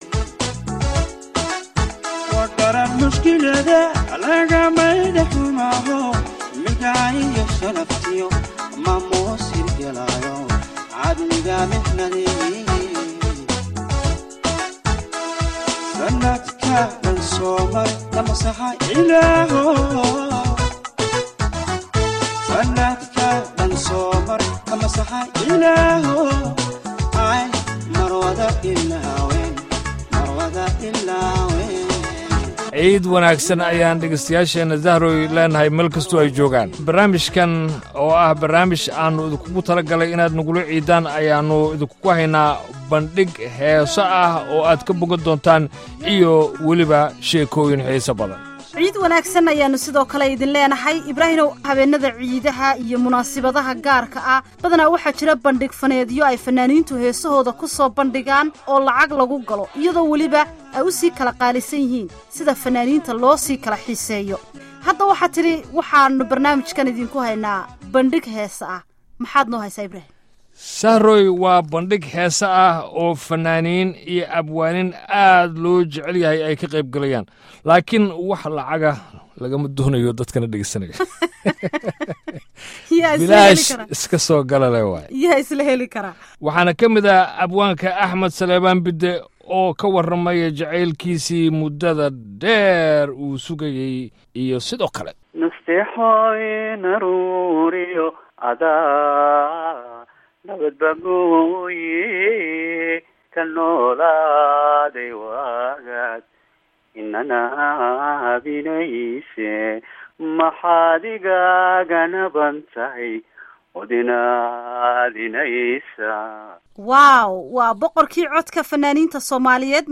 Barnaamijka Ciidda ee VOA waxaa ka qeybgalay fannaaniin uu ka mid yahay boqorka codka Maxamed Suleymaan Tubeec.